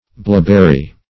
bleaberry - definition of bleaberry - synonyms, pronunciation, spelling from Free Dictionary Search Result for " bleaberry" : The Collaborative International Dictionary of English v.0.48: Bleaberry \Blea"ber*ry\, n. (Bot.)
bleaberry.mp3